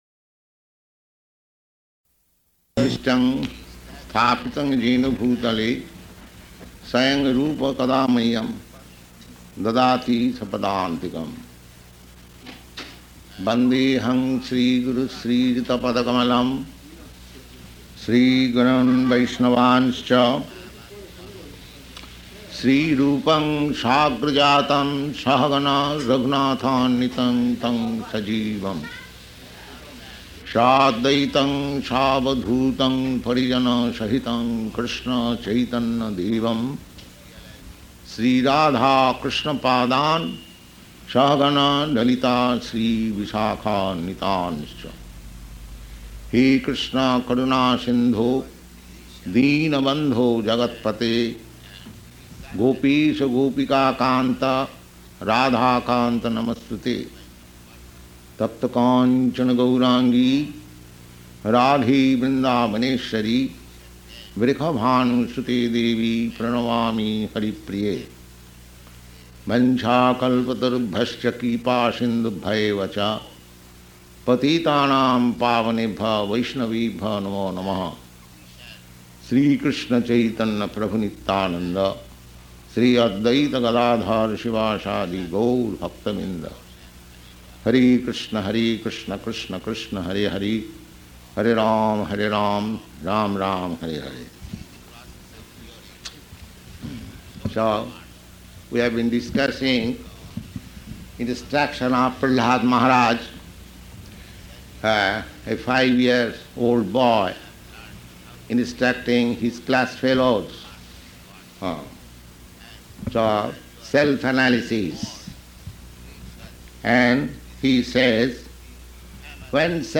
Location: San Francisco
[chants maṅgalācaraṇa prayers]